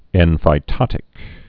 (ĕnfī-tŏtĭk)